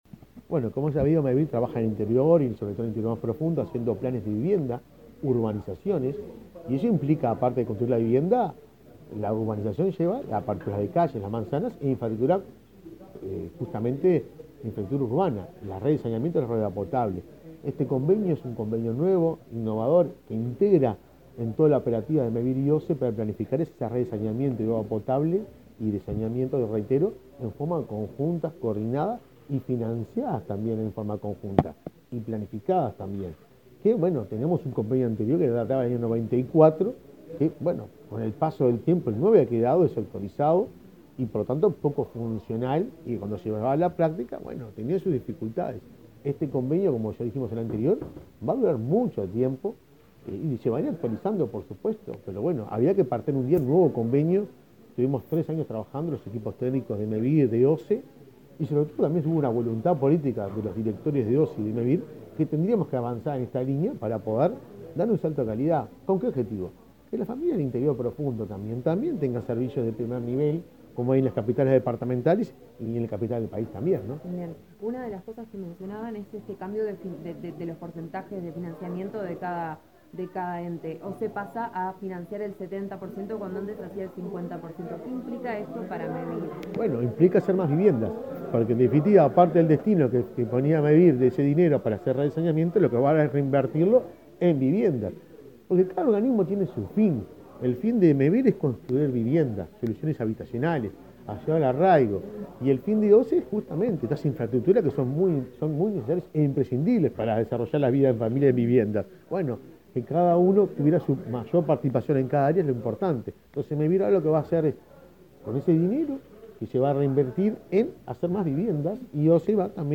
Declaraciones del presidente de Mevir, Juan Pablo Delgado